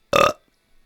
Fast Burp
belch burp human male sound effect free sound royalty free Memes